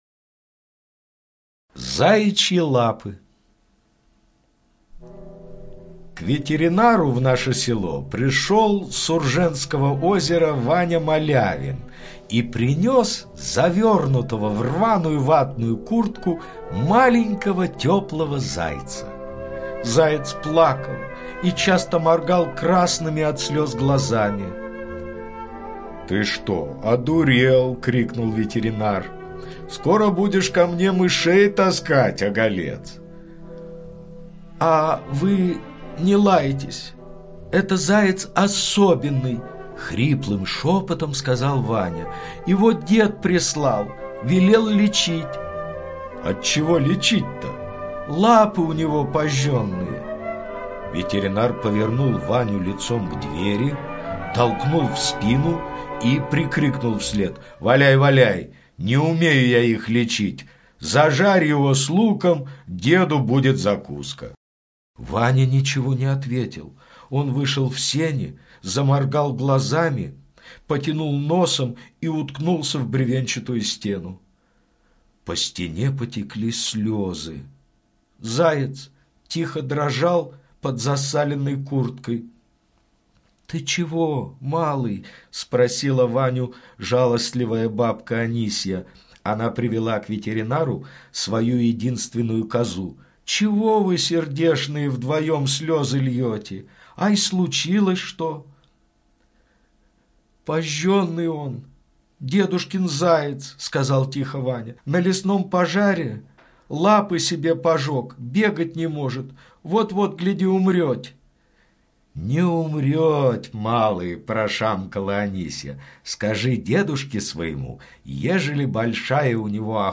Заячьи лапы - аудио рассказ Паустовского К. История про то, как заяц во время сильного лесного пожара спас жизнь деду.